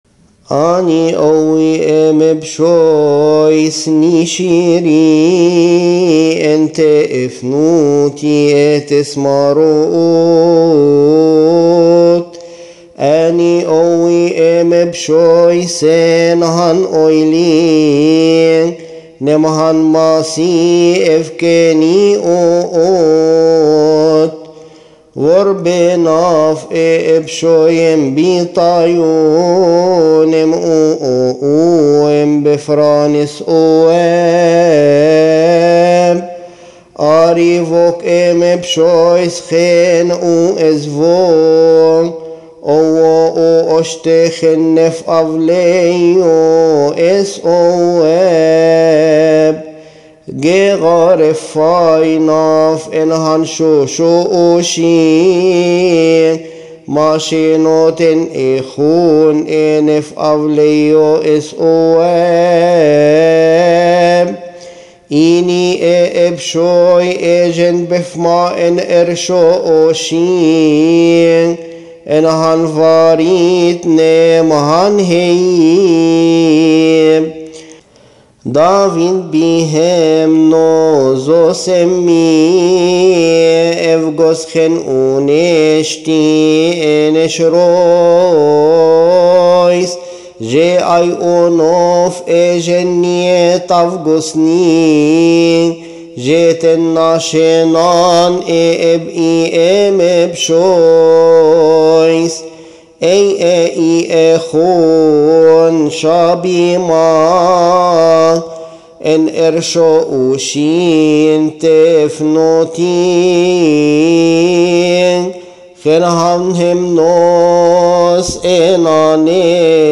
إبصالية واطس لعيد الختان وعيد دخول السيد المسيح الهيكل
المرتل